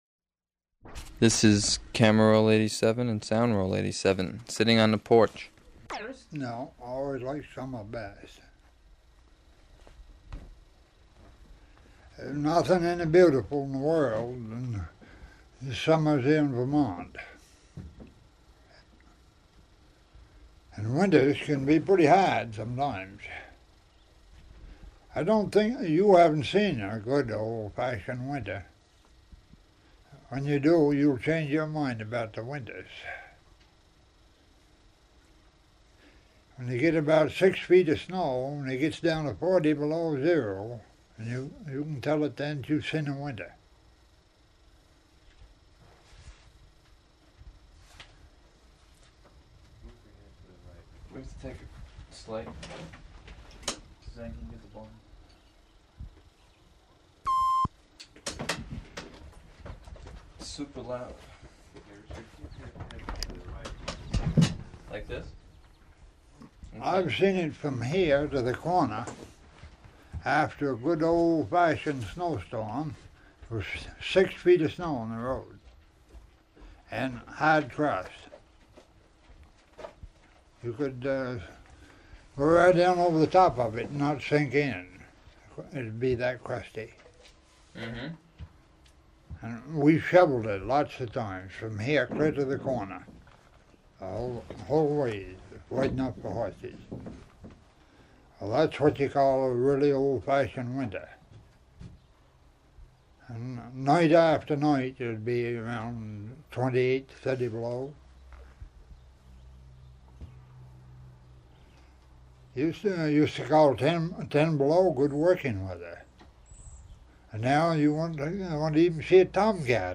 Format 1 sound tape reel (Scotch 3M 208 polyester) : analog ; 7 1/2 ips, full track, mono.
Chelsea (inhabited place) Vermont (state)